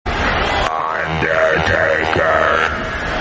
Kane Says Undertaker's Name (1999)
Category: Television   Right: Personal